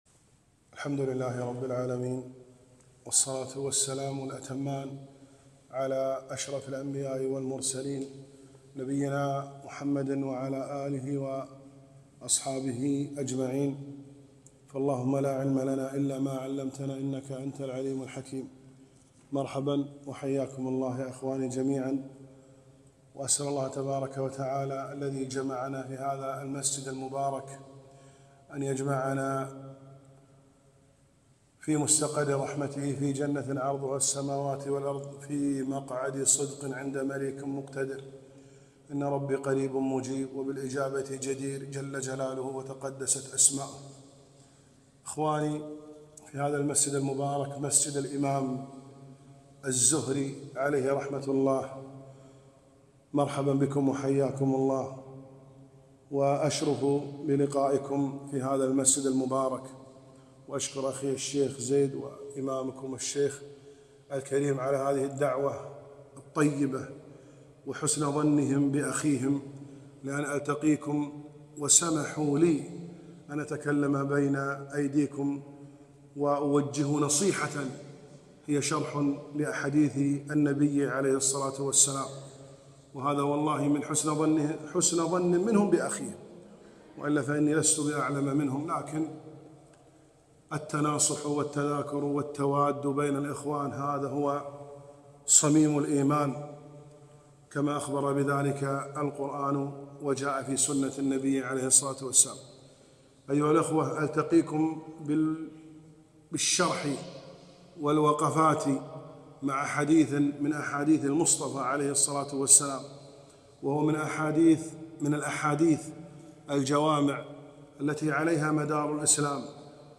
محاضرة نافعة - الدين النصيحة